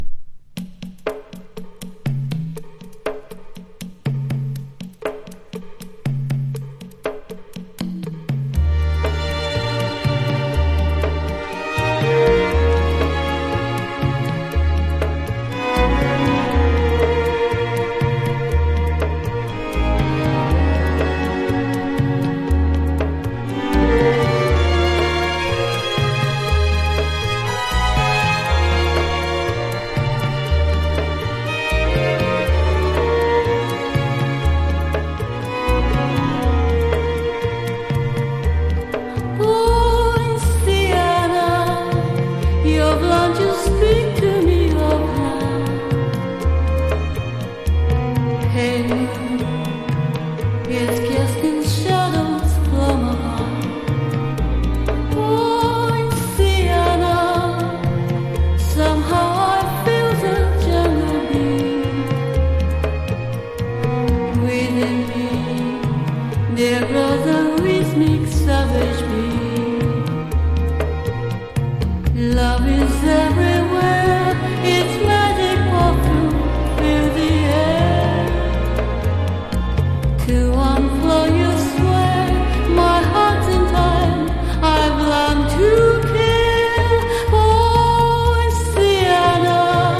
タイトル通りムード・アレンジのイージー・リスニングなカヴァー曲多数収録。